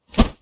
Close Combat Swing Sound 43.wav